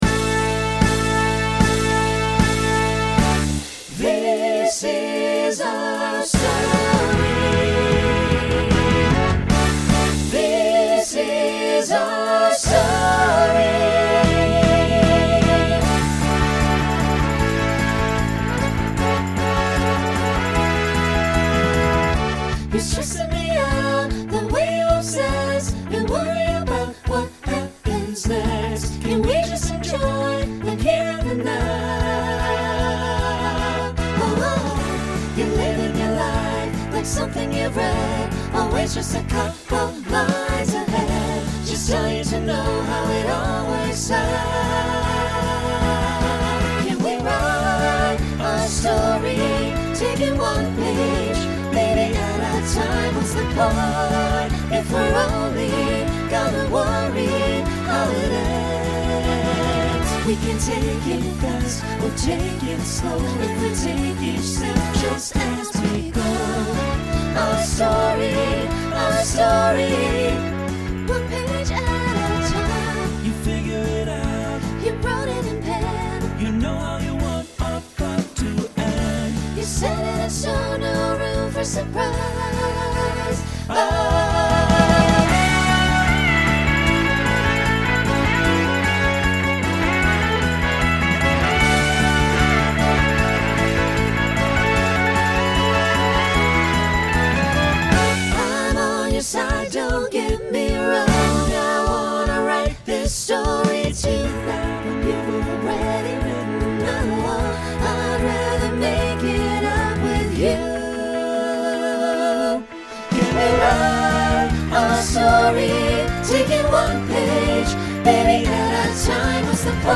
Genre Broadway/Film , Rock
Voicing SATB